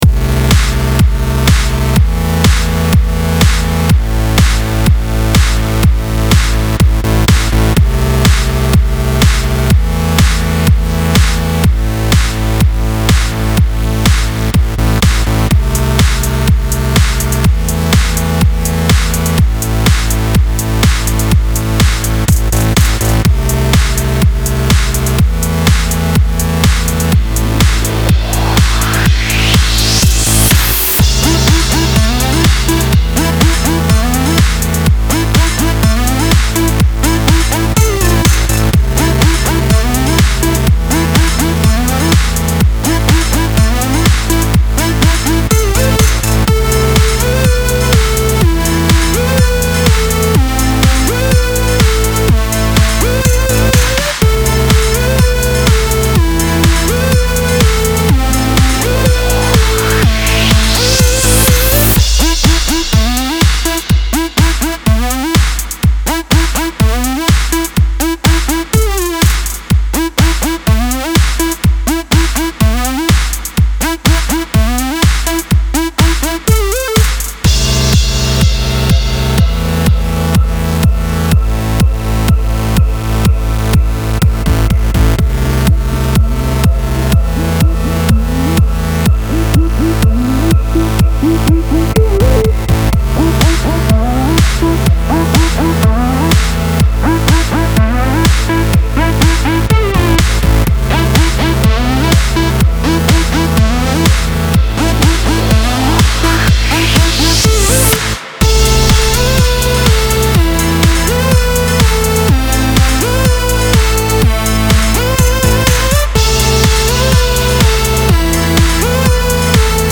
Style Style EDM/Electronic
Mood Mood Aggressive, Epic, Uplifting
Featured Featured Bass, Drums, Synth
BPM BPM 124
It’s like a musical energy drink!